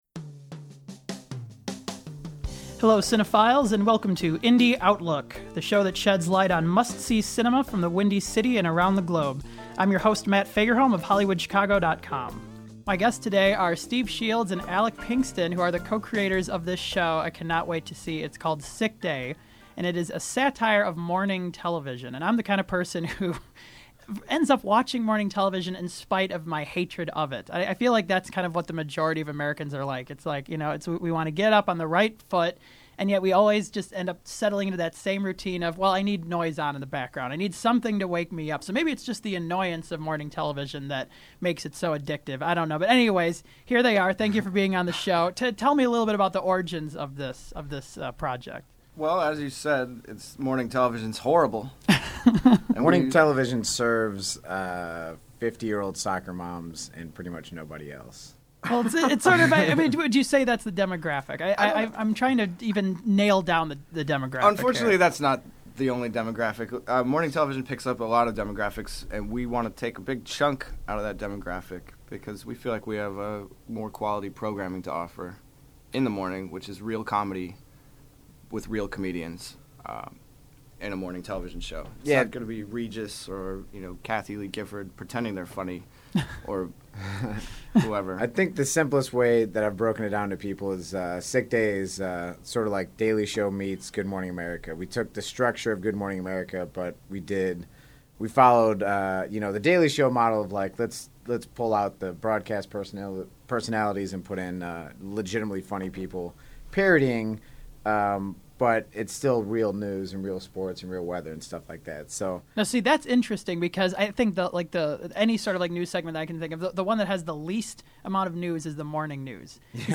The program was produced at Columbia College Chicago.